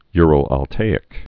(yrəl-ăl-tāĭk)